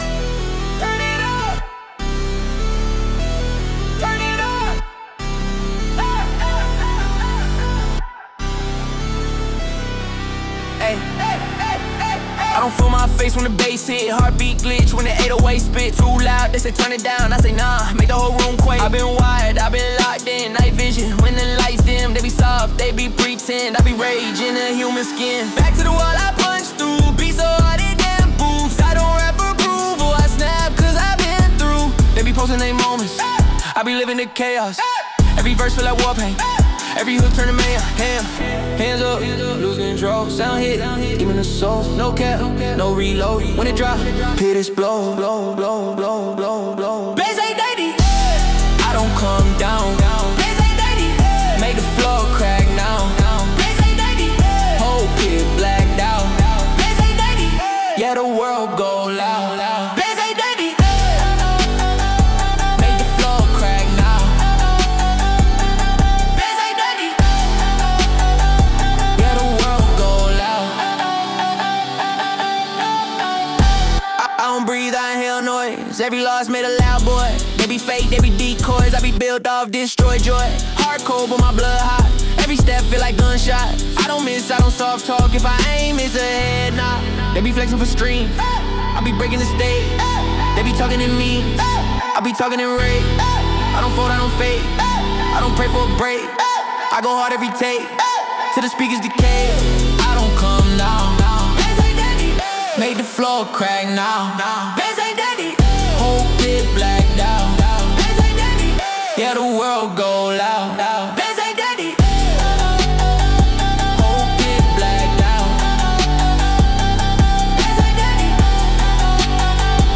Genre: Trap Mood: Vibin'